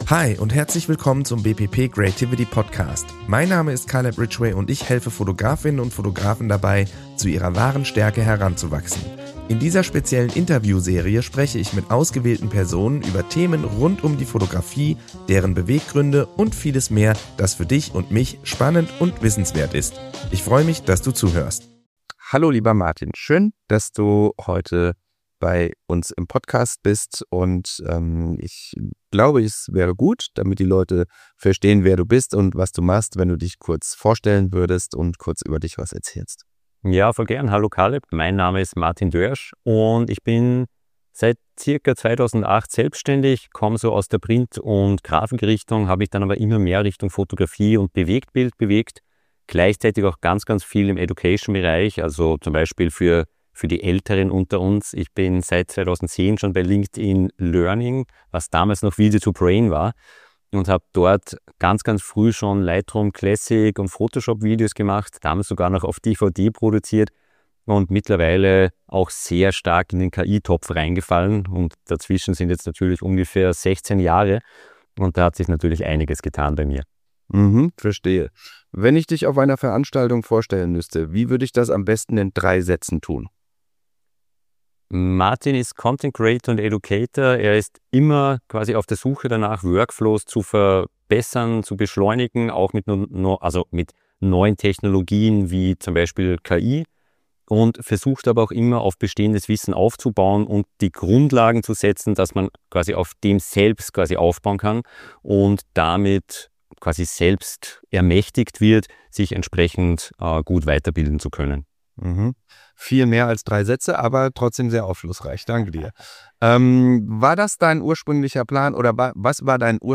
120. Interview Special 02